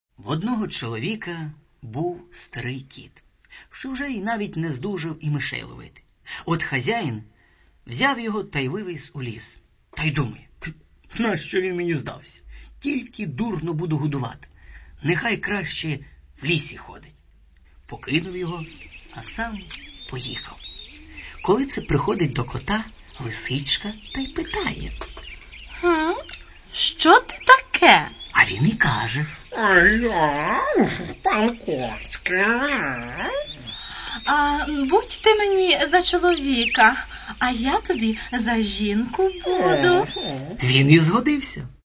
И не просто так начитанных – а рассказанных ярко, образно, эмоционально (и правда, хорошо здесь поработали актеры юмористического театра "КУМ"). Еще и с музыкальным сопровождением – как же без музыки?